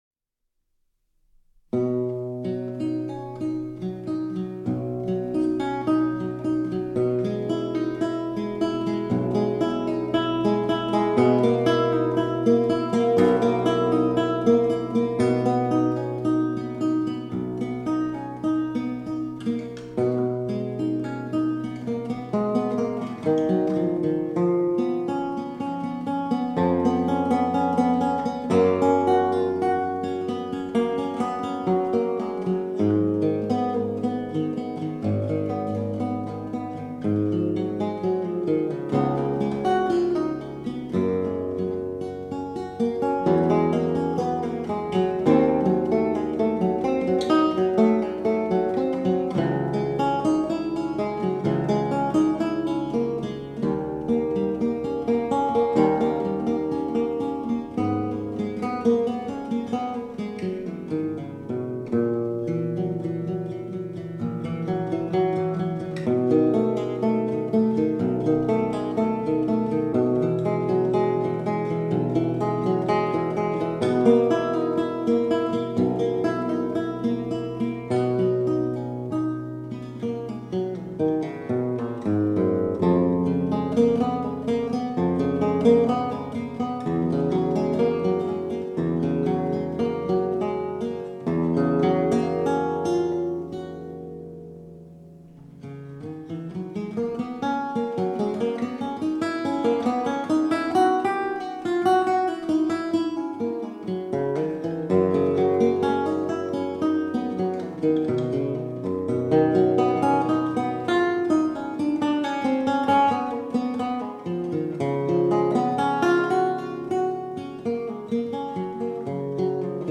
Lute
Houghton Memorial Chapel